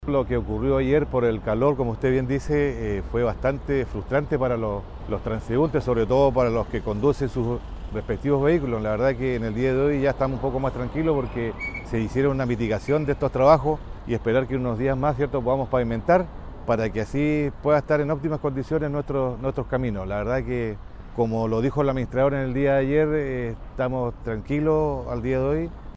Ante este escenario, el alcalde de la comuna, Baltazar Elgueta, informó que se realizaron diversos trabajos de mitigación para reducir los riesgos.
alcalde-por-pavimentos-.mp3